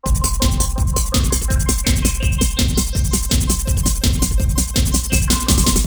__BEEP POP 1.wav